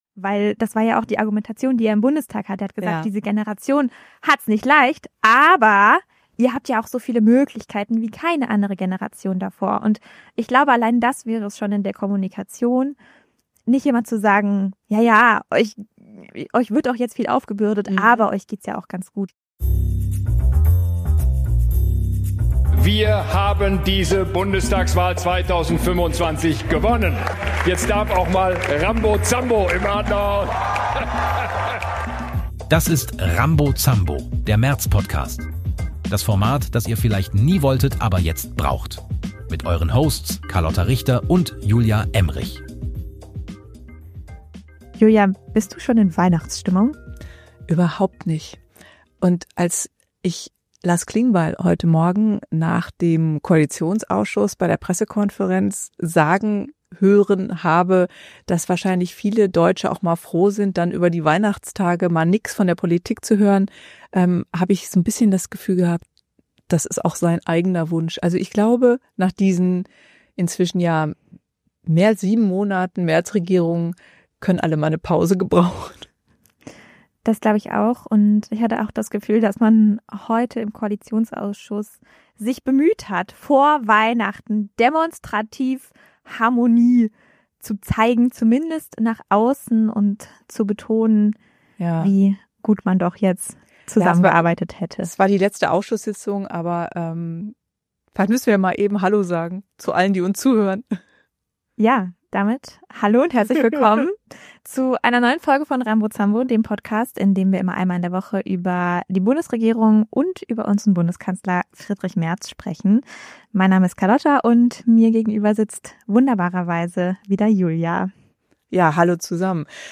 Zwei Frauen.